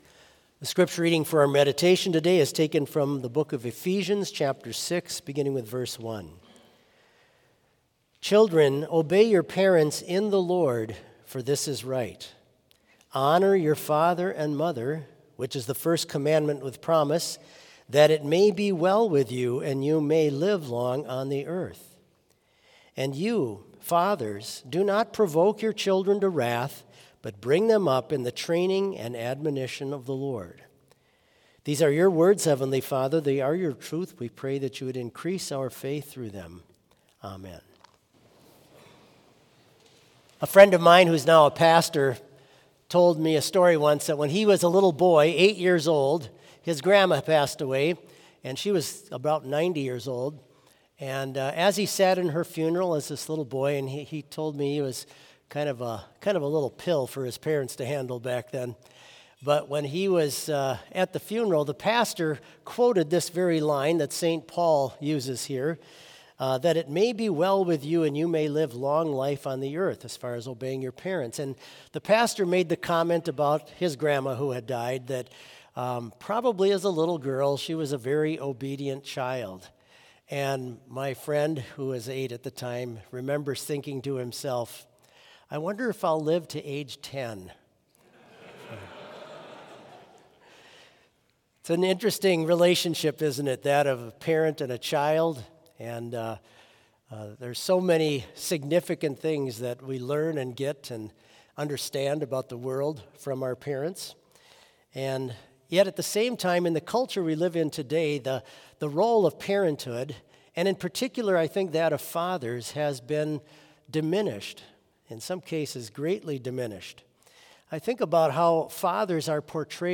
Complete service audio for Chapel - Monday, January 13, 2025